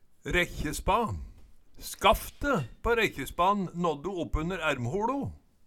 Høyr på uttala Ordklasse: Substantiv hankjønn Kategori: Reiskap og arbeidsutstyr Attende til søk